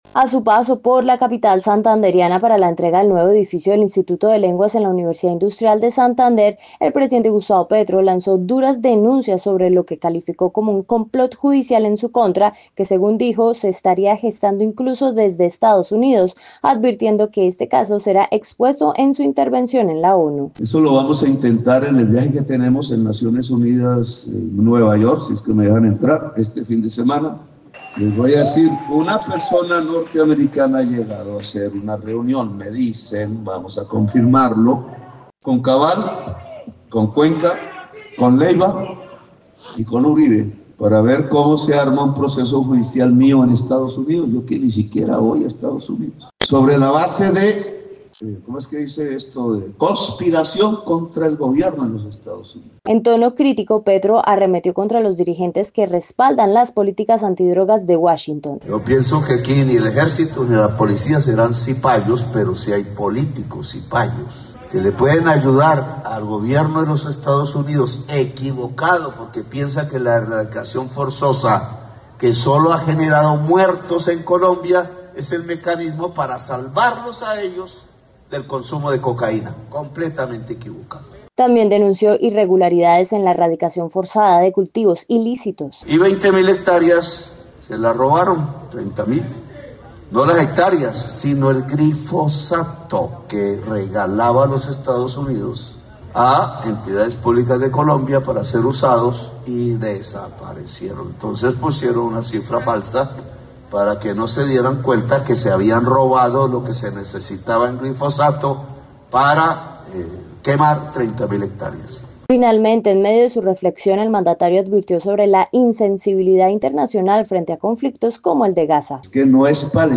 Presidente Gustavo Petro
A su paso por la capital santandereana para la entrega del nuevo edificio del Instituto de Lenguas en la Universidad Industrial de Santander, el presidente Gustavo Petro lanzó duras denuncias sobre lo que calificó como un “complot judicial en su contra” que, según dijo, se estaría gestando incluso desde Estados Unidos.